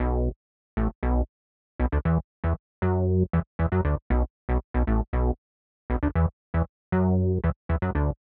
11 Bass PT2.wav